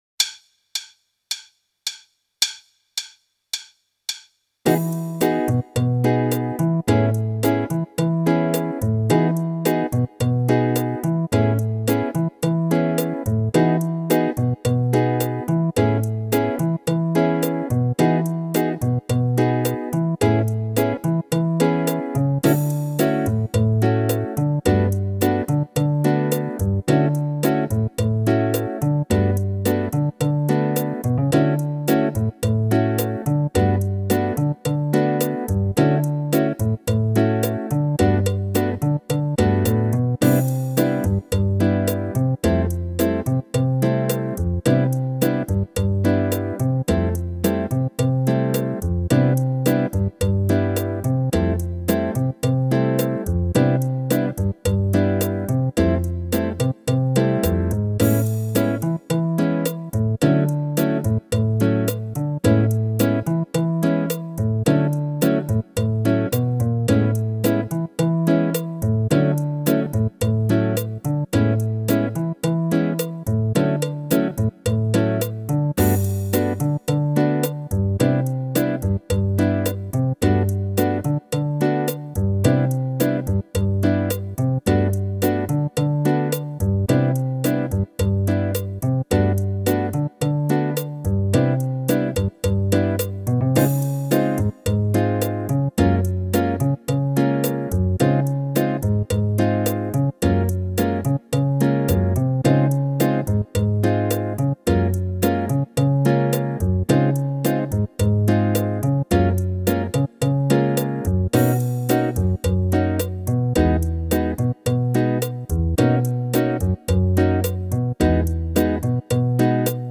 Meespeel CD
9. Meer drumritmes